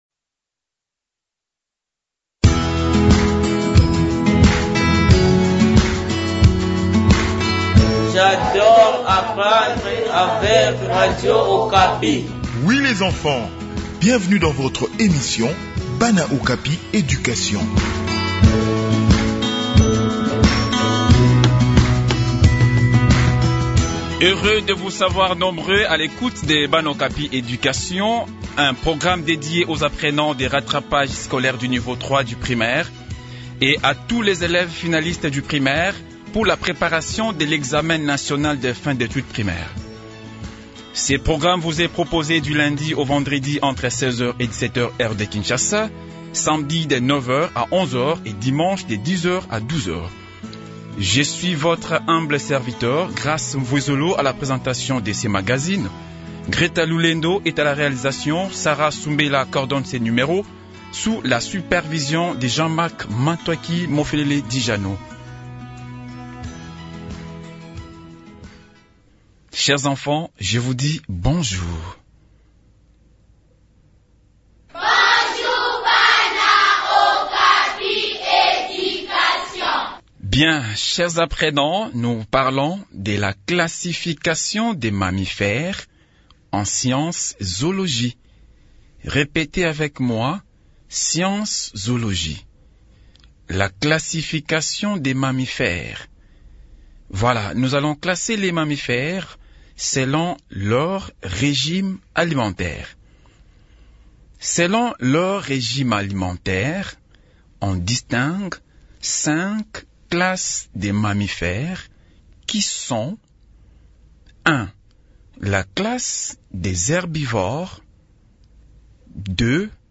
Préparation aux examens nationaux : leçon de Zoologie sur les mammifères